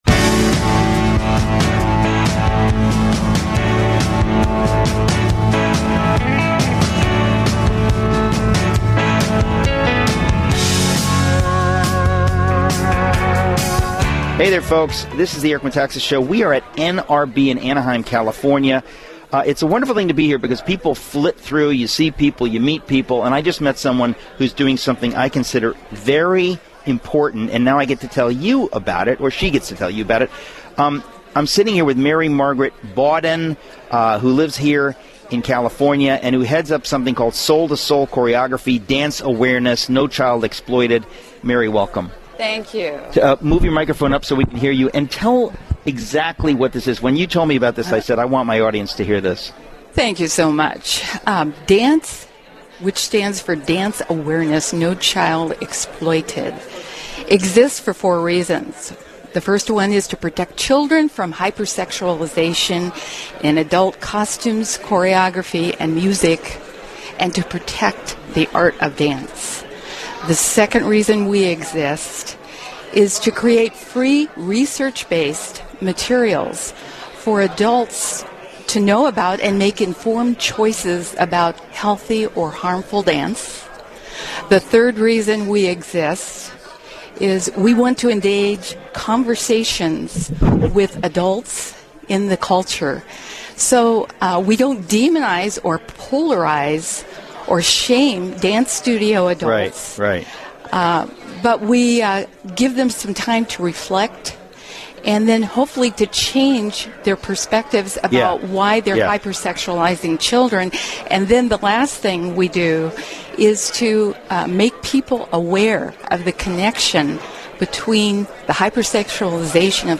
The Eric Metaxas Show DA:NCE Interview - DA:NCE Dance Awareness No Child Exploited
at the NRB Conference. The topic was on the hypersexualization of children in dance with adult costumes, choreography, and music.